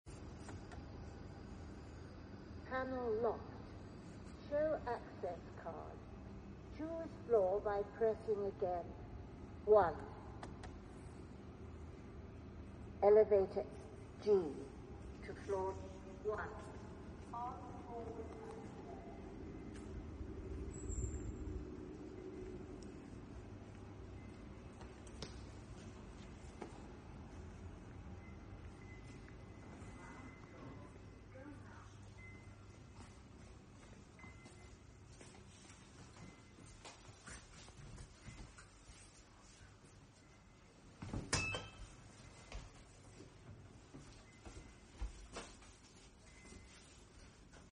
A Kone Polestar elevator lift sound effects free download
A Kone Polestar elevator lift on Collins Street